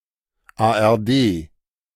1. ^ German pronunciation: [ˌaːʔɛʁˈdeː]